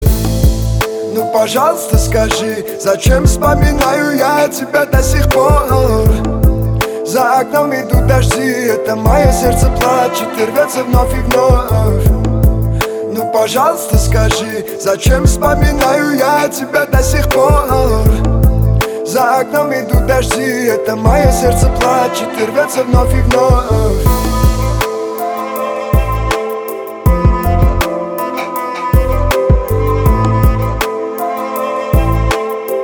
• Качество: 320, Stereo
восточные мотивы
лирика
Хип-хоп
грустные
русский рэп